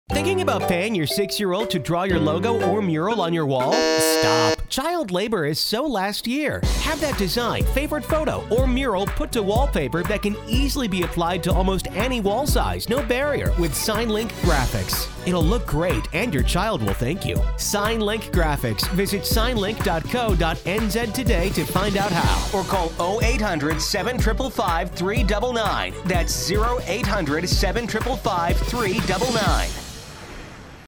The whole commercial is not packed with jokes, its quick – to the point and compelling.